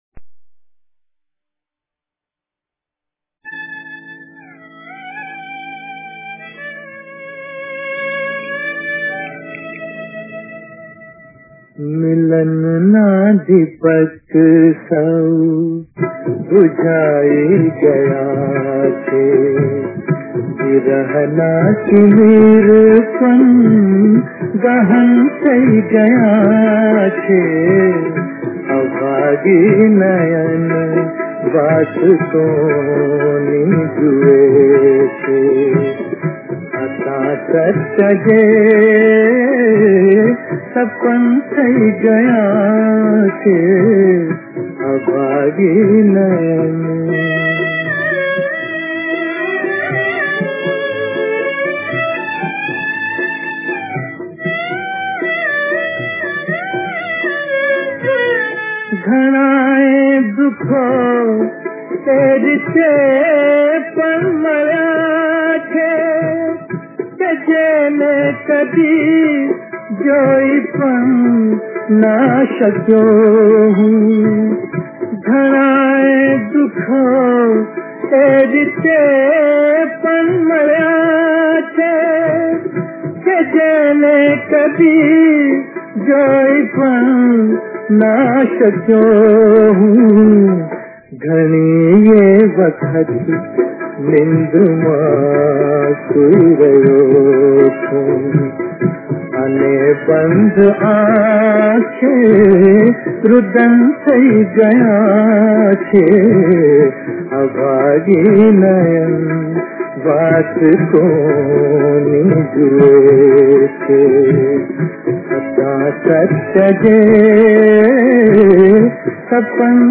નોંધઃ ગ્રામોફોન રેકોર્ડના જમાનામાં